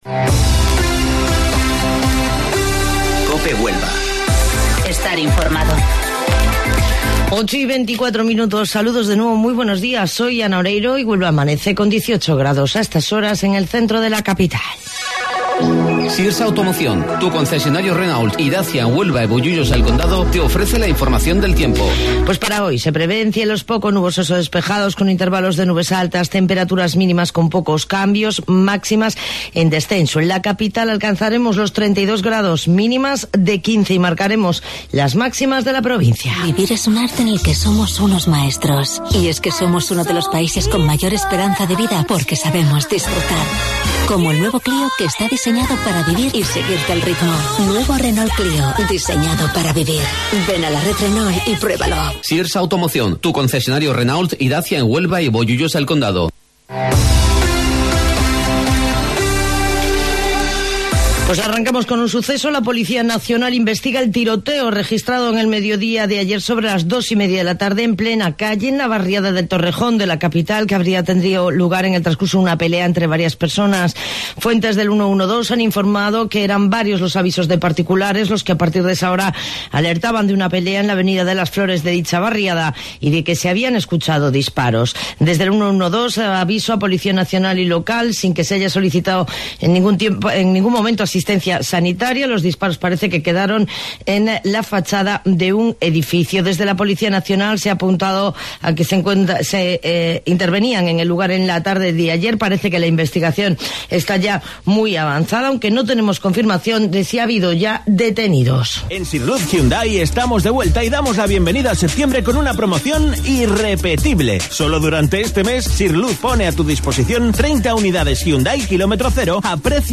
AUDIO: Informativo Local 08:25 del 25 de Septiembre